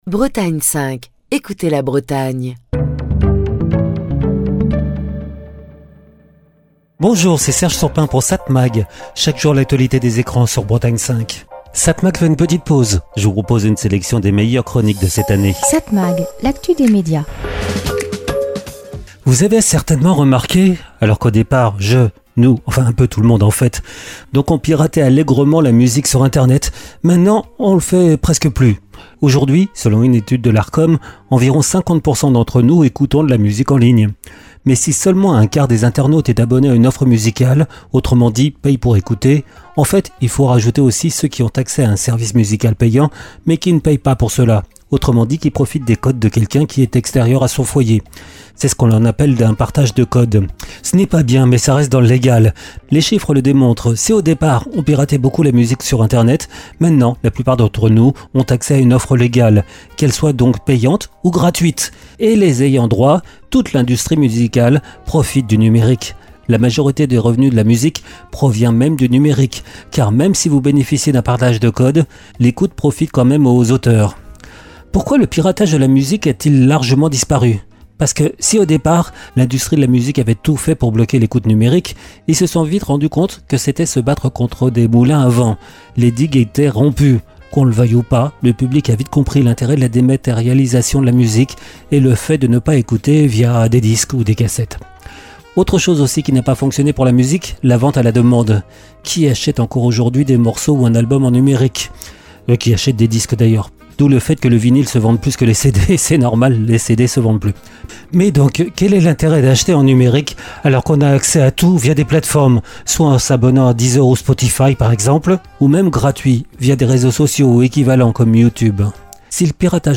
Chronique du 25 juillet 2025.